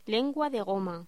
Locución: Lengua de goma
voz